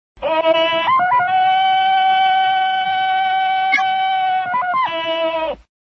Y para ponerte en ambiente, dale al Play antes de empezar!!
Grito-do-Tarzan.mp3